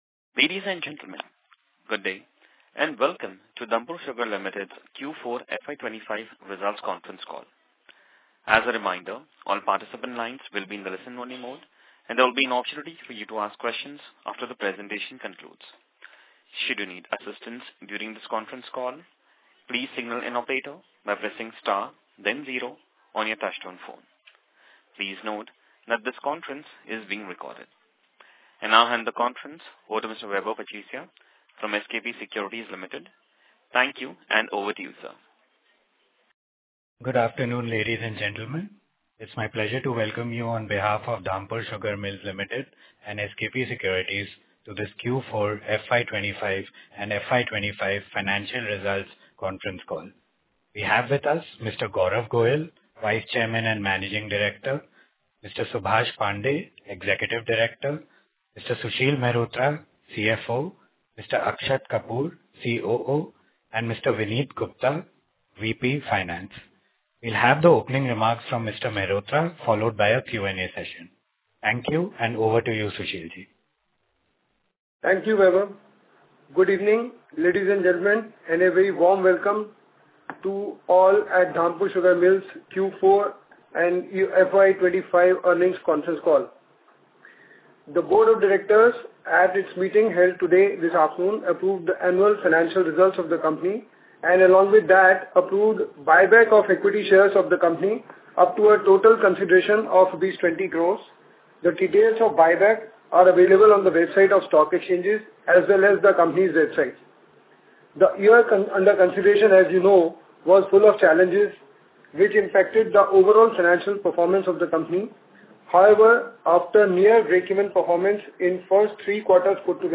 Earnings Call Audio